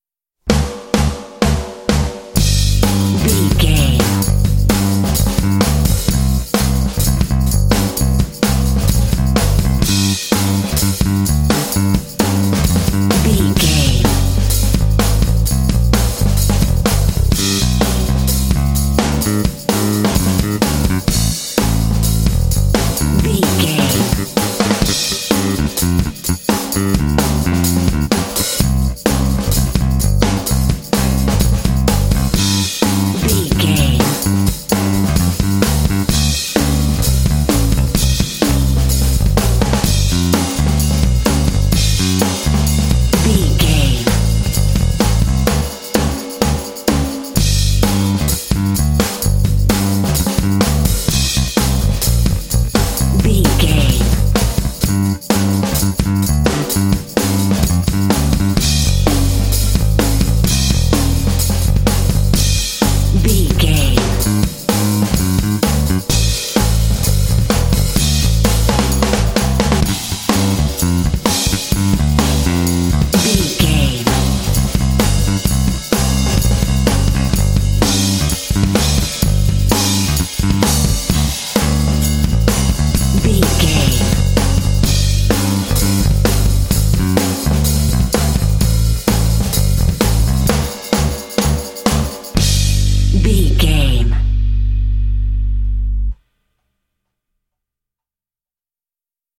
Aeolian/Minor
intense
driving
energetic
groovy
funky
drums
bass guitar
Funk
blues